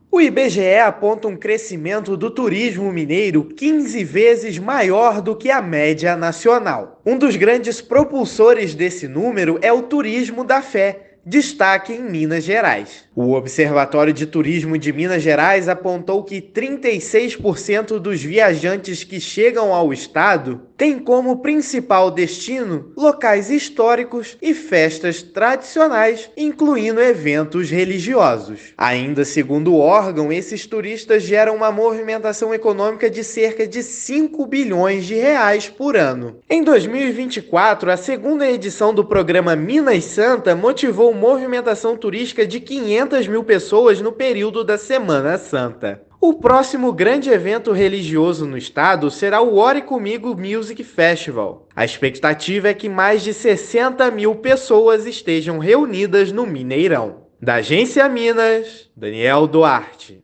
[RÁDIO] Turismo da fé em Minas Gerais gera movimentação econômica de R$ 5 bilhões por ano